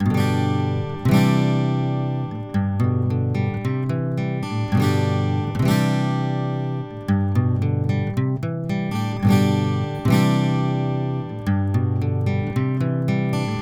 Pour les prises de son, j’ai utilisé un préamplificateur Neve 4081 quatre canaux avec la carte optionnelle Digital l/O qui convertit l’analogique en numérique AES sur une SubD25 ou en Firewire.
Les échantillons n’ont subi aucun traitement.
Prise de son 3 : LCT640 – DPA 4011.
Enregistrements d’une guitare acoustique (AIFF) :